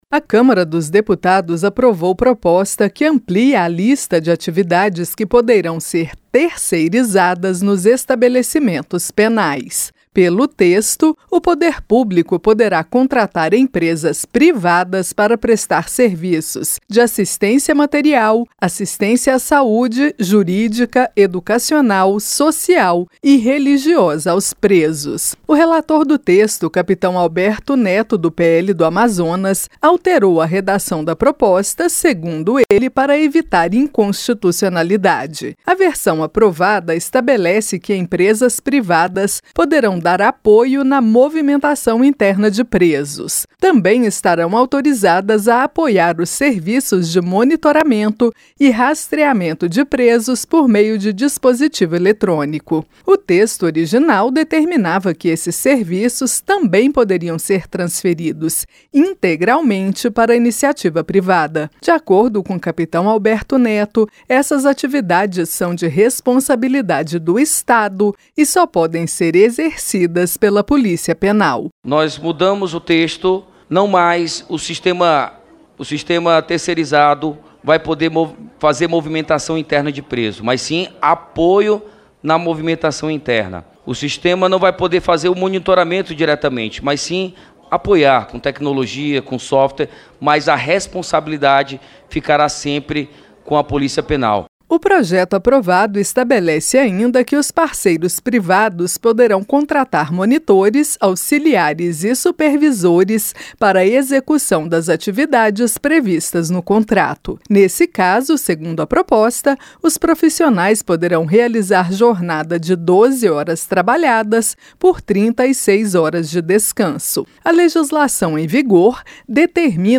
A CÂMARA APROVOU PROJETO QUE AMPLIA A PRIVATIZAÇÃO EM ESTABELECIMENTOS PRISIONAIS. ENTENDA NA REPORTAGEM